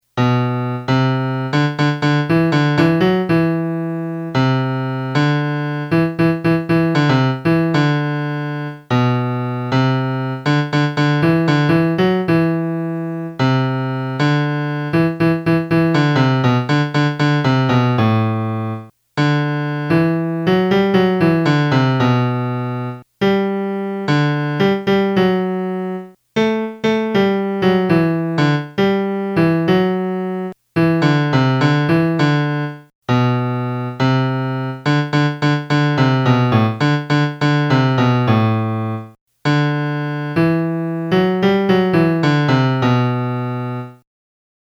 la-domenica-melody.mp3